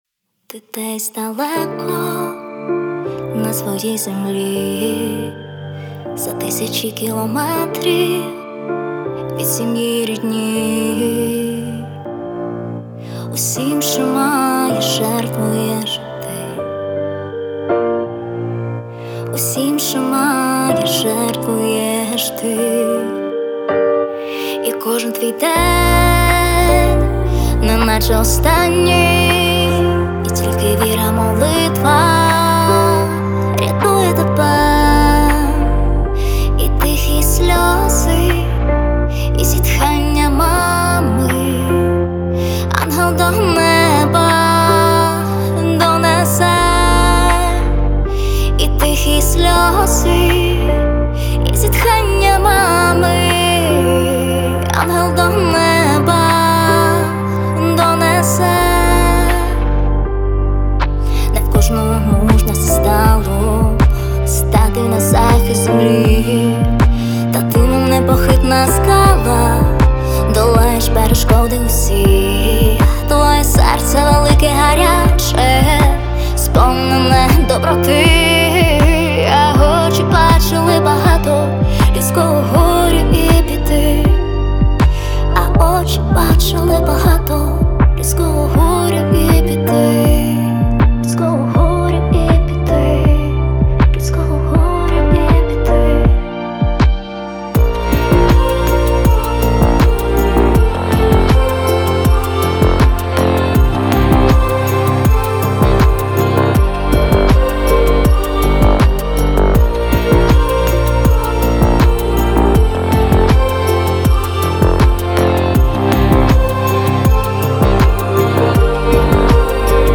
Pop лірика